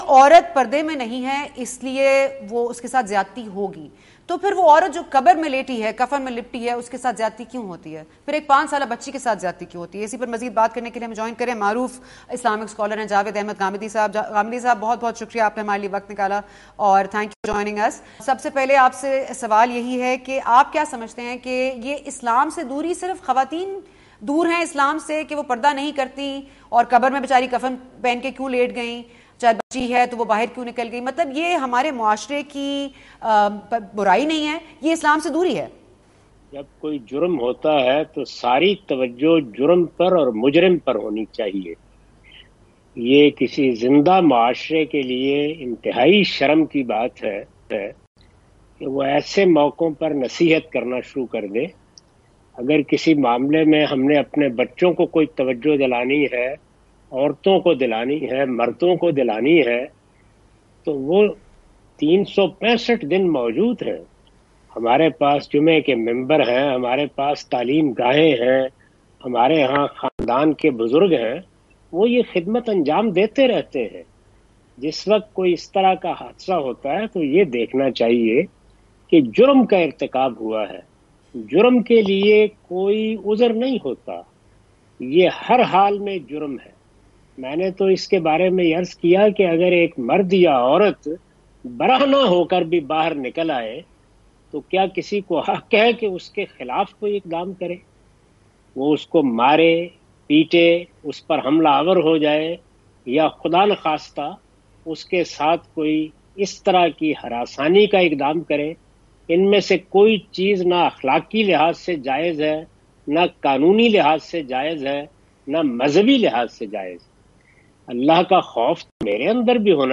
Special Interview Of Ghamdi On Minar E Pakistan Incident l Goonj 20 Aug 2021 | 24 News HD Host : Sana Bucha